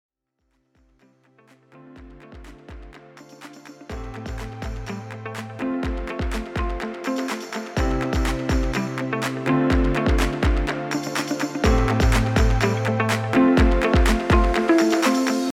callerTune.901708b1.mp3